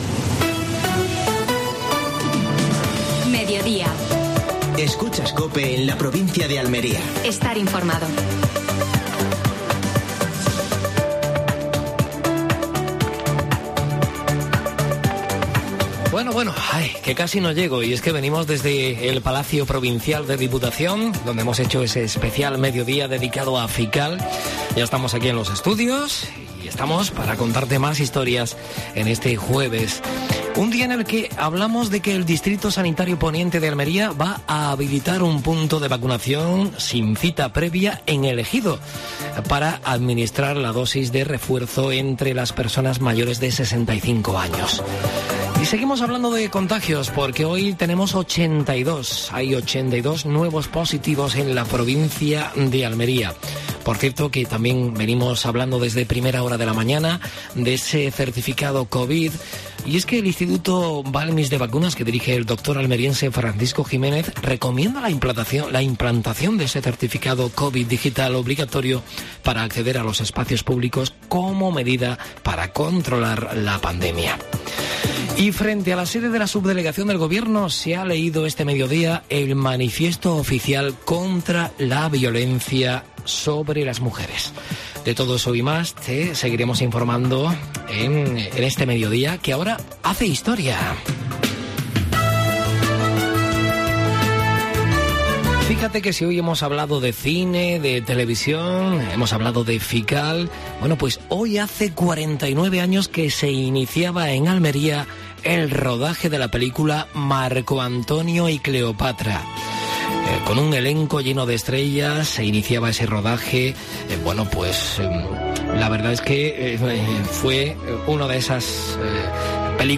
AUDIO: Actualidad en Almería. Entrevista a Margarita Cobos (concejal del Ayuntamiento de Almería).